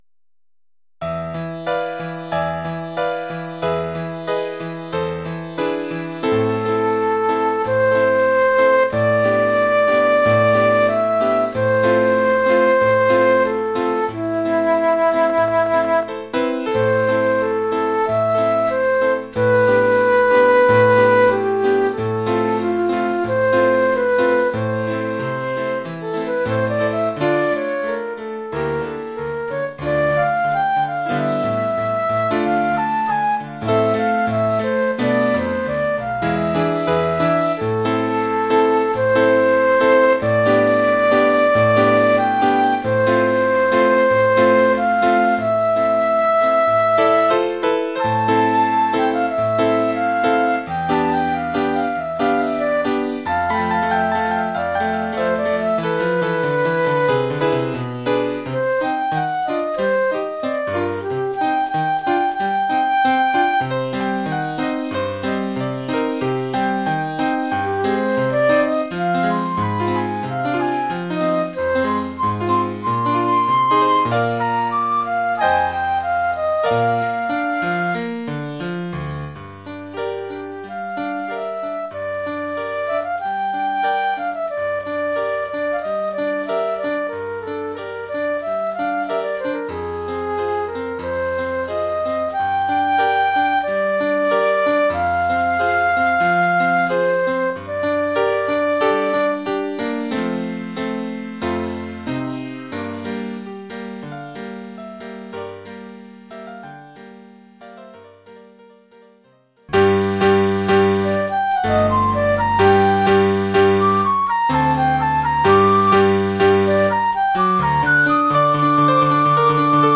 Formule instrumentale : Flûte et piano
Oeuvre pour flûte et piano.